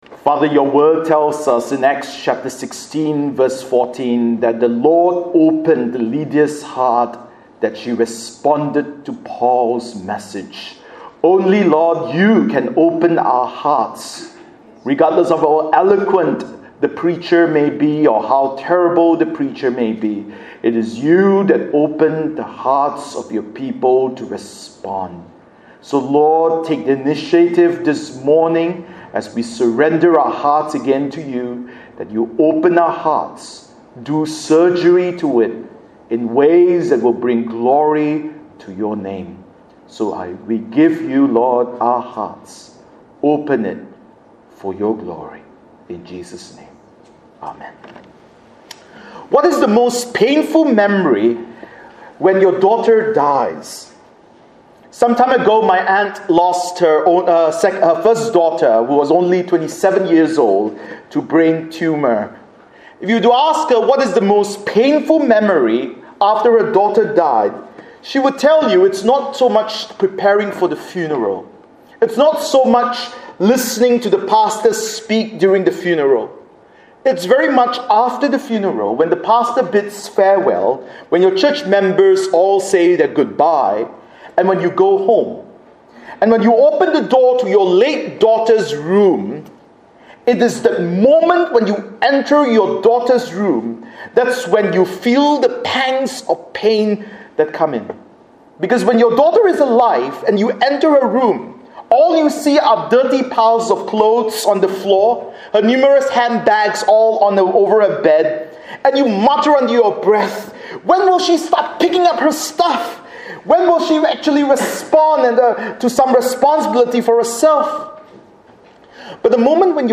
Bible Text: Judges 13:1-14 | Preacher